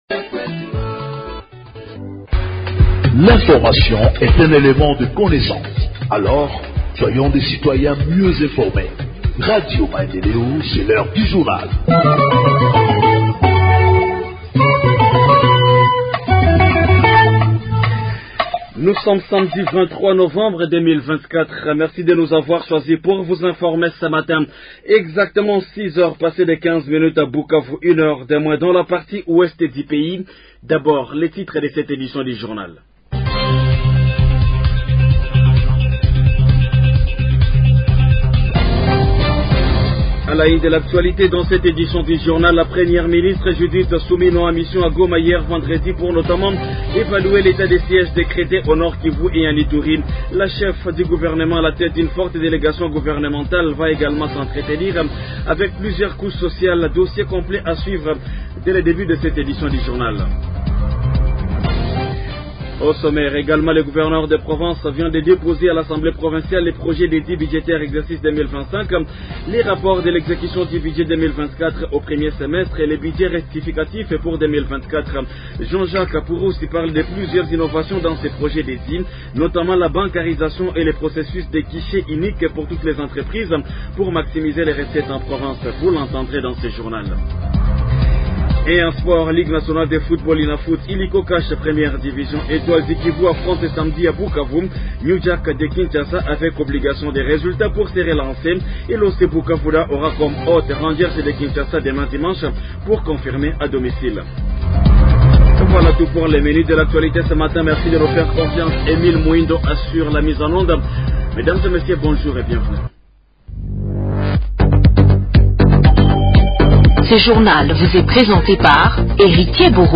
Journal Français du 23 novembre 2024 – Radio Maendeleo